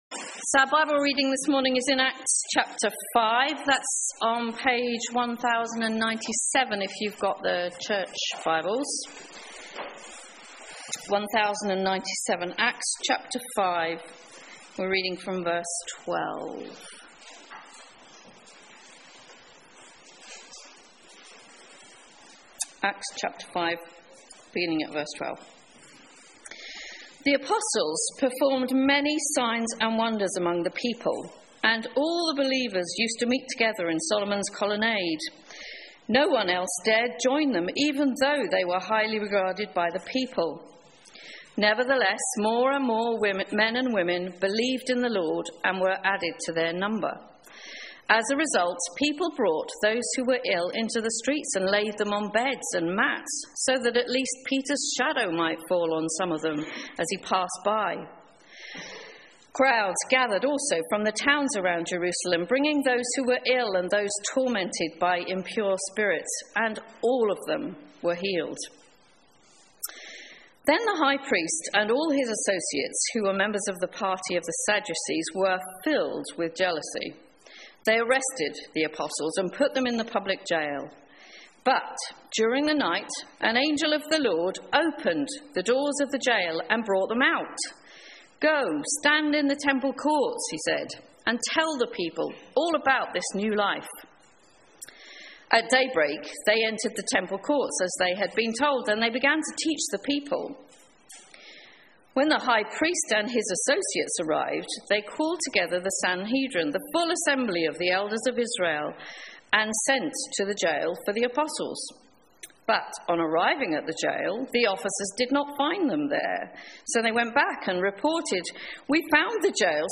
Bible Reading Sermon Scripture 12 Now many signs and wonders were regularly done among the people by the hands of the apostles.